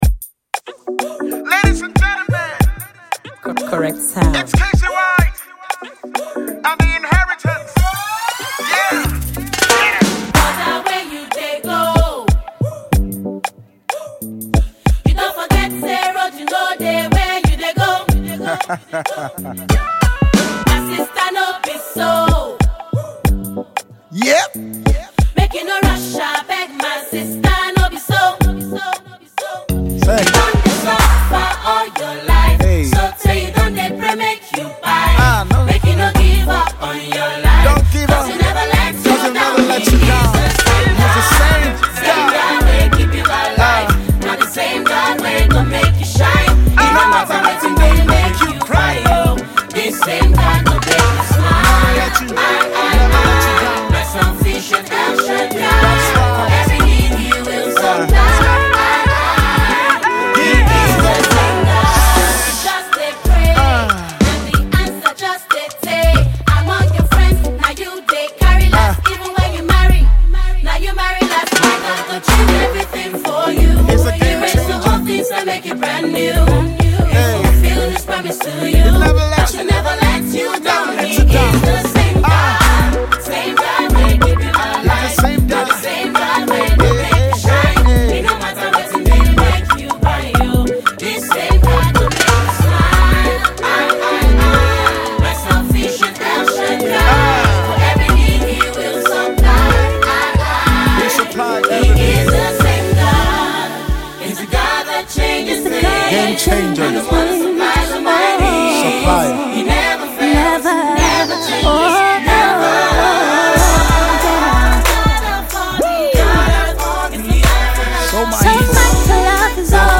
melodious song